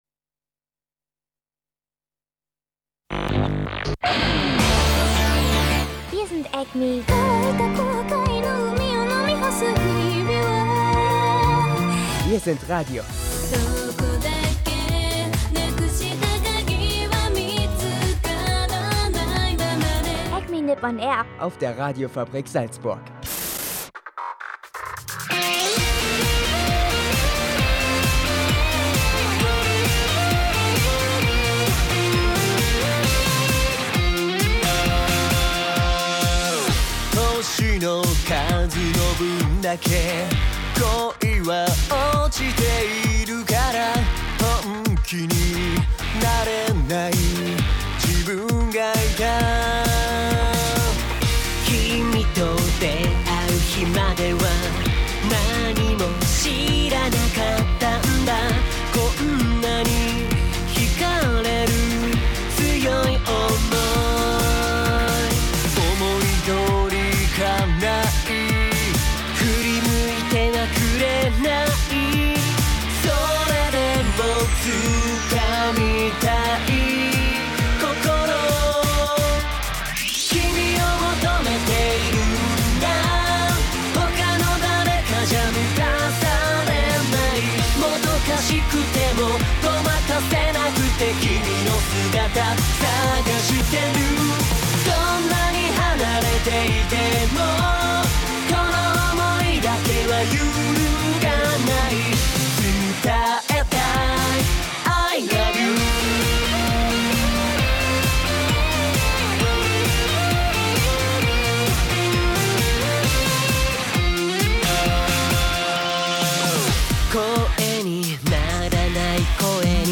Und natürlich Anime-Musik en masse.